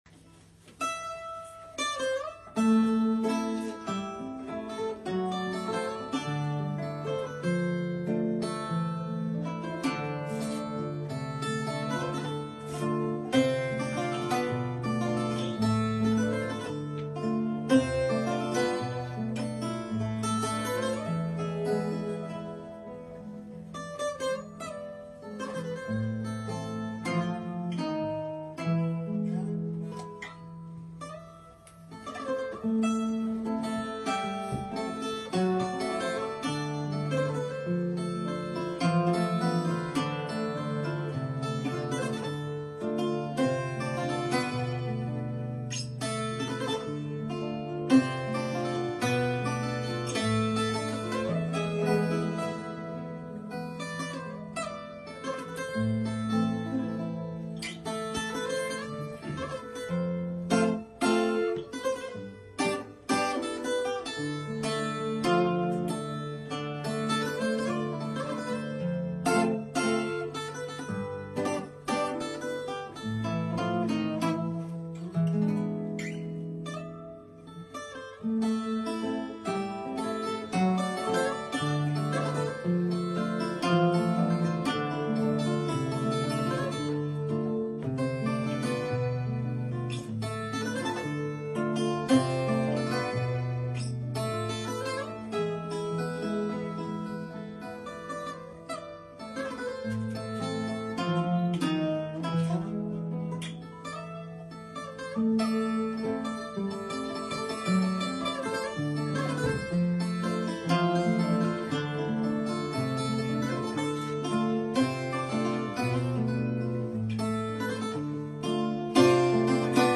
Consulado de Portugal em Andorra 🇵🇹🤝🇦🇩 Guitarra Portuguesa
Guitarra Clássica